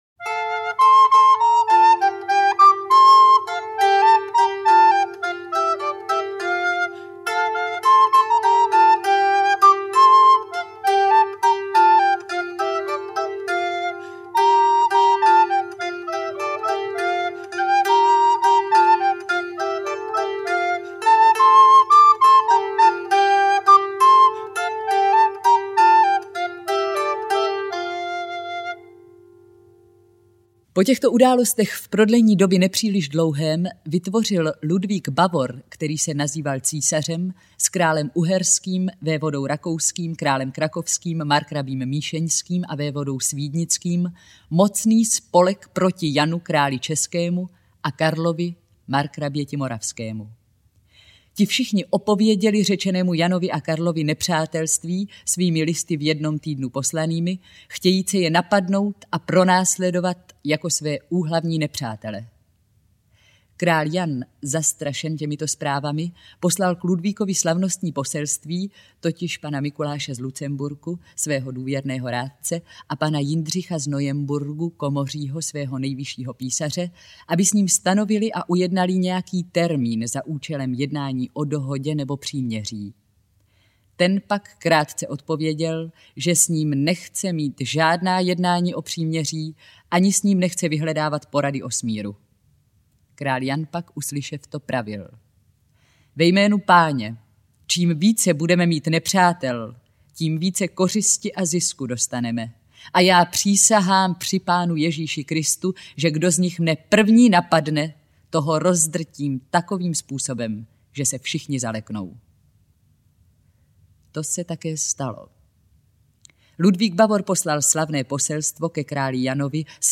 • InterpretNina Divíšková, Jan Kačer